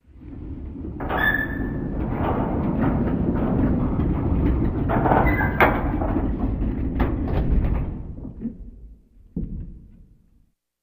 Castle Portcullis Door Slide